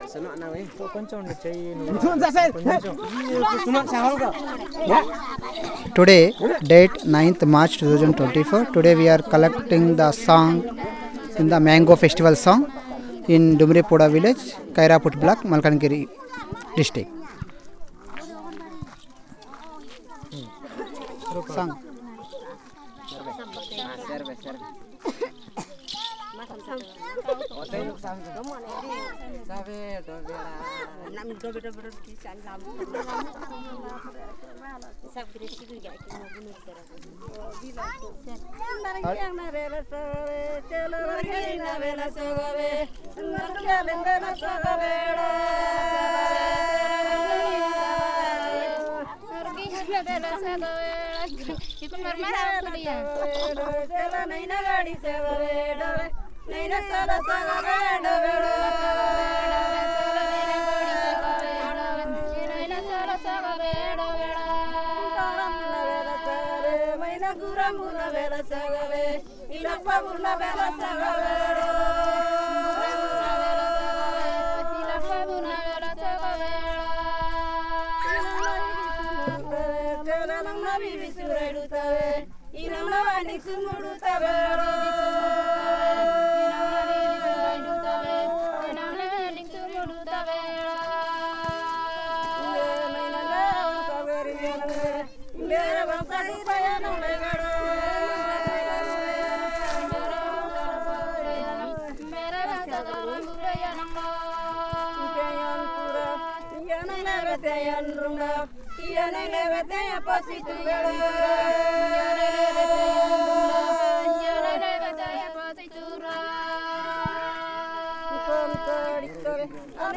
Elicitation of a song celebrating the Mango Festival